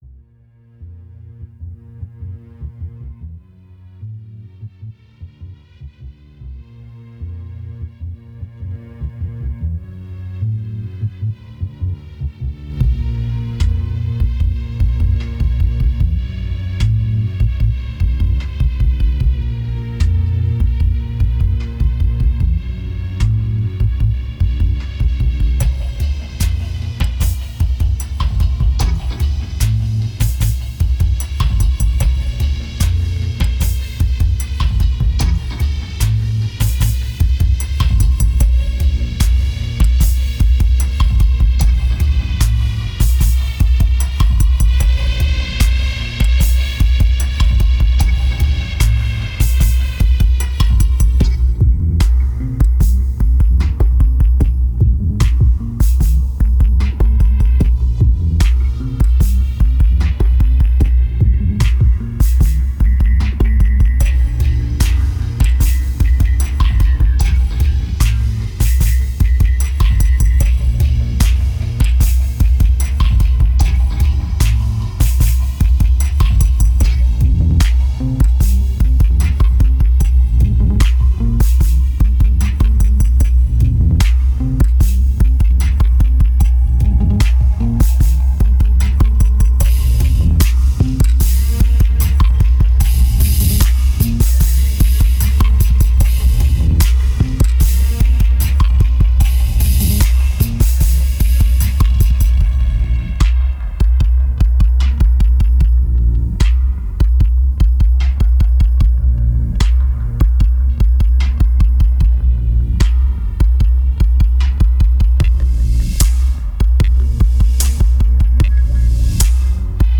2259📈 - -34%🤔 - 137BPM🔊 - 2009-04-24📅 - -321🌟